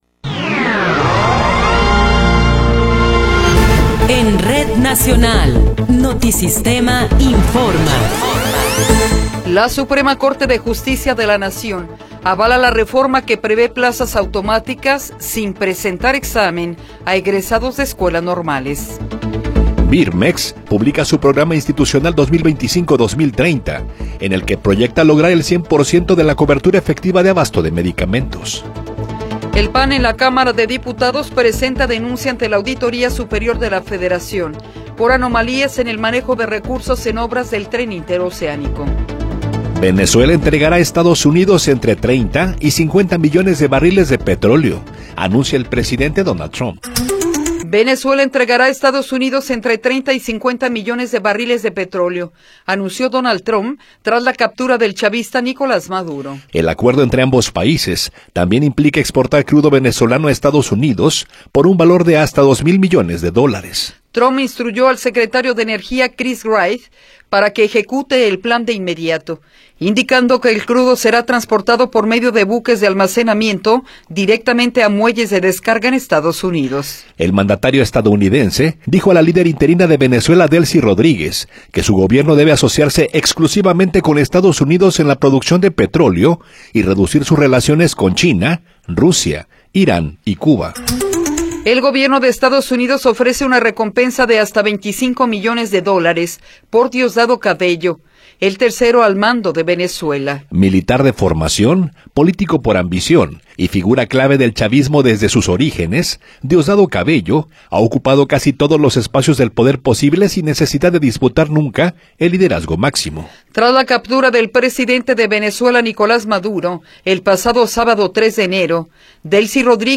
Noticiero 8 hrs. – 7 de Enero de 2026
Resumen informativo Notisistema, la mejor y más completa información cada hora en la hora.